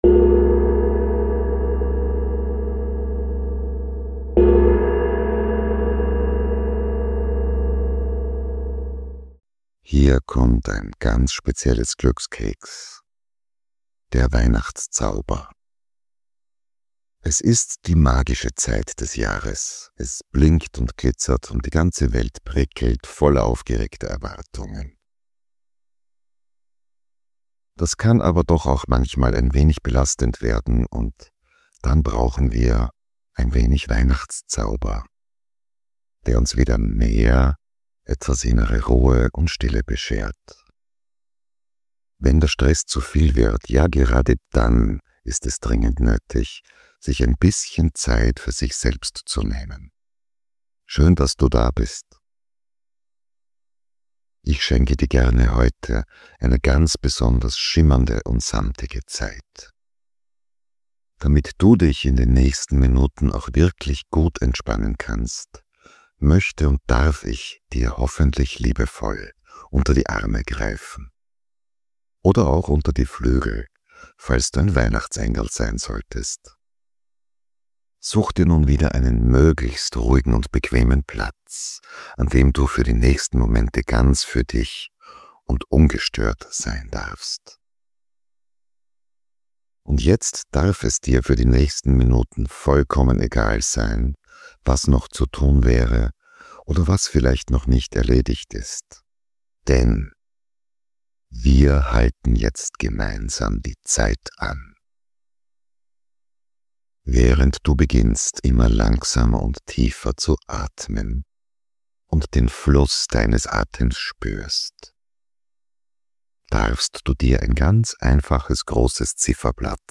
Weihnachtszauber Meditation für Ruhe, Stille & Entspannung